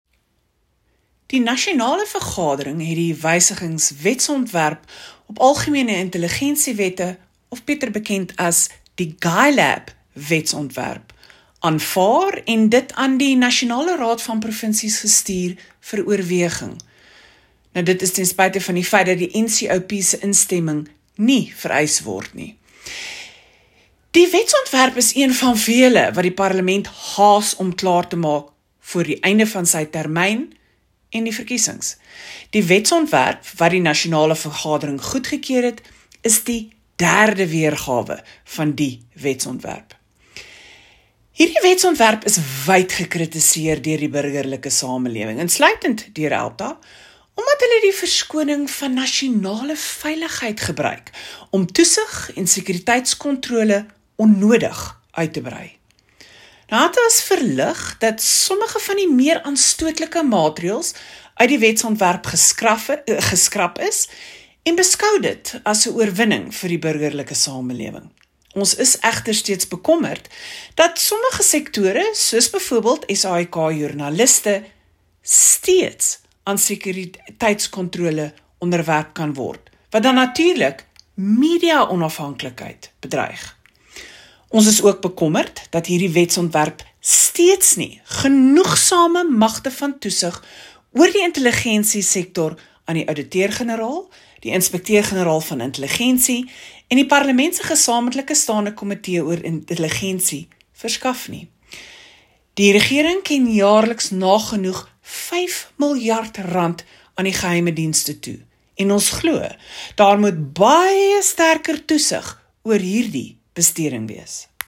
A soundclip with comment in Afrikaans